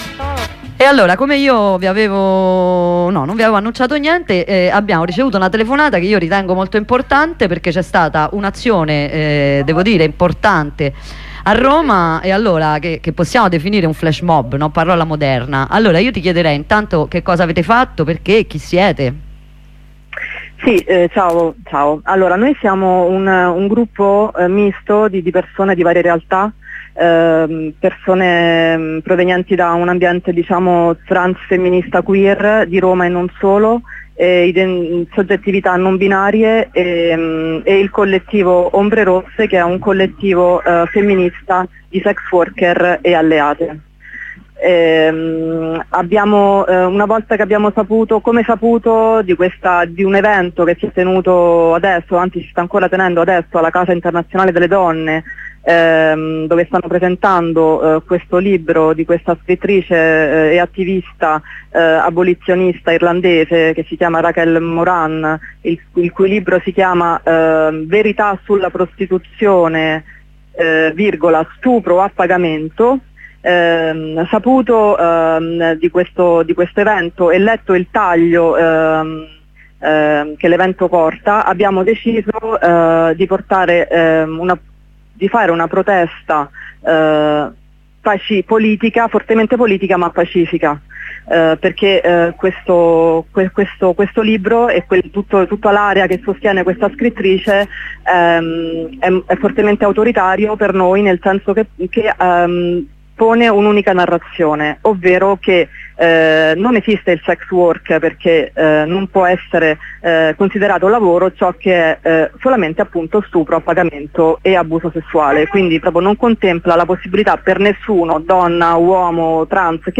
Ieri durante la presentazione del libro di Rachel Moran  'Stupro a pagamento, la verità sulla prostituzione' alcune realtà hanno effettuato un pacifico flash mob. Le abbiamo sentite in una corrispondenza, qui sotto il volantino che hanno lasciato all'iniziativa.